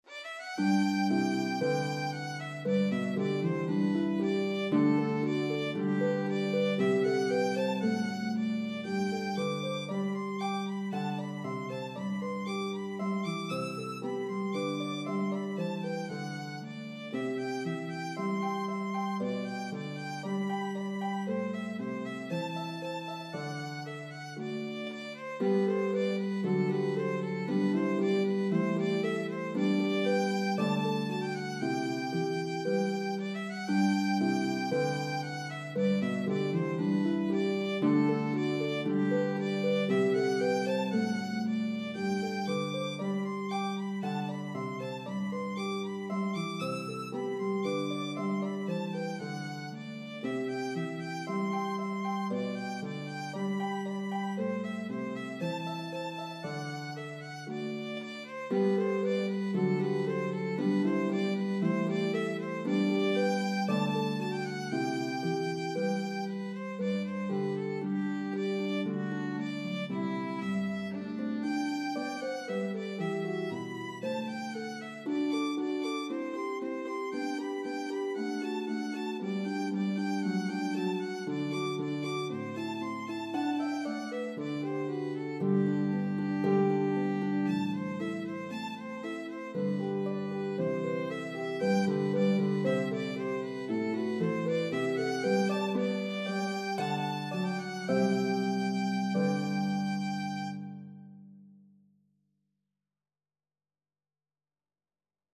popular Italian style of the day